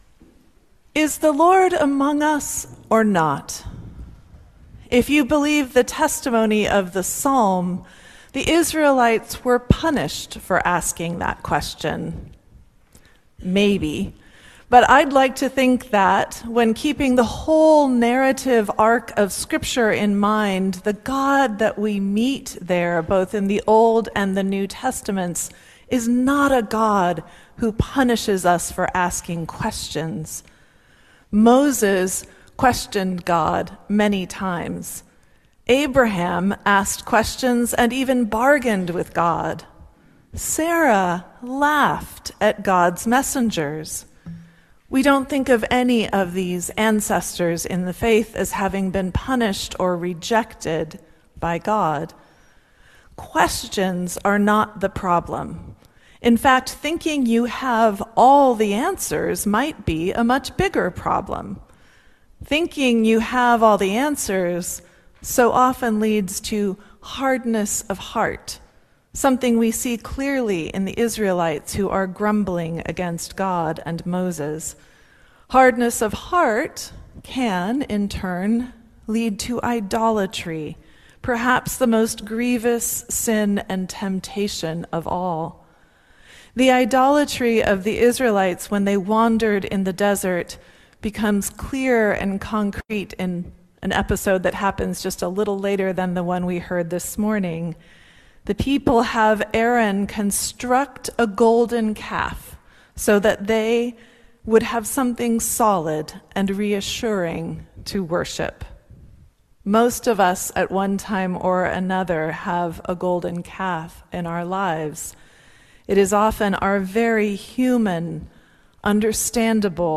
Sermon on March 8